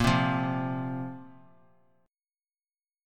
Bbsus2 chord